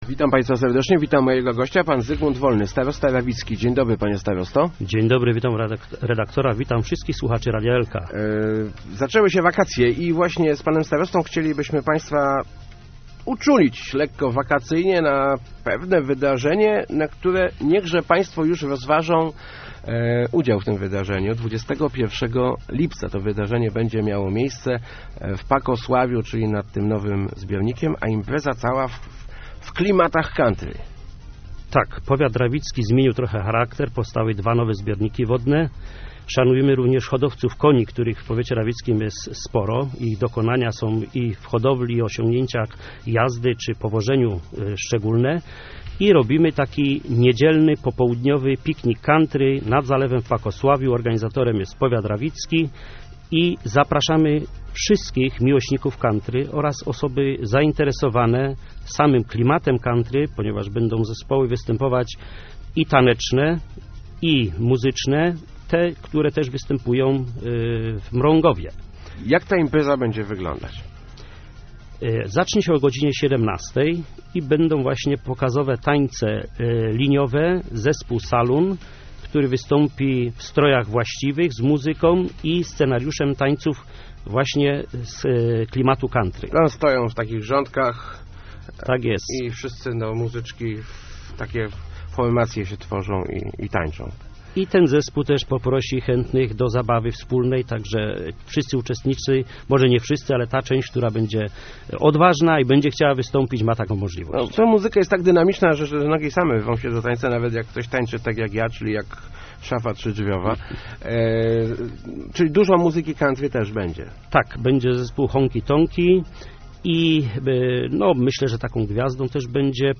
zwolny.jpgChcemy pokazać nasz powiat z zupełnie nowej strony - mówił w Rozmowach Elki starosta rawicki Zygmunt Wolny. W niedzielę 21 lipca organizuje on Piknik Country, który odbędzie się nad zalewem w Pakosławiu. Królować będzie tam muzyka i tańce z Dzikiego Zachodu.